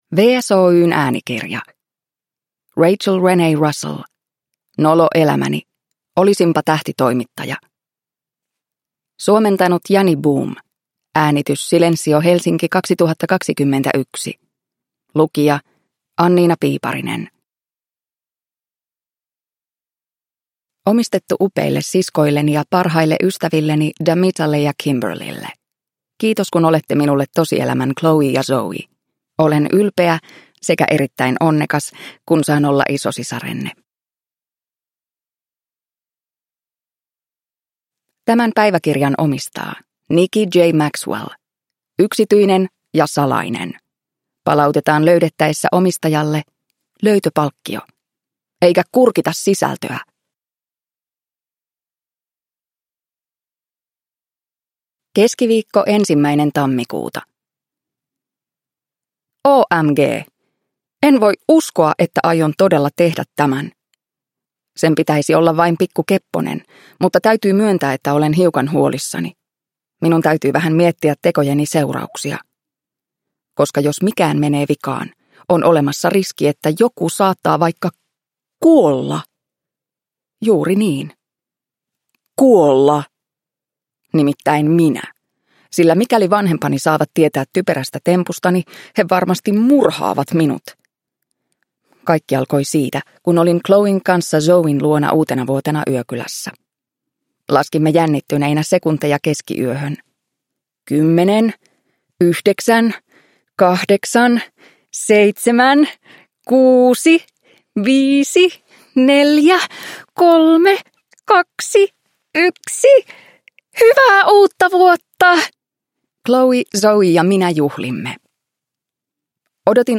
Nolo elämäni: Olisinpa tähtitoimittaja – Ljudbok – Laddas ner